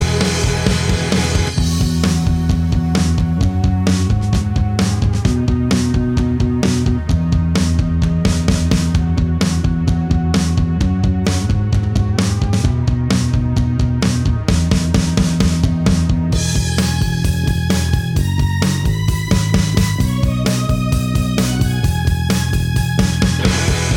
No Lead Guitar Pop (2010s) 3:40 Buy £1.50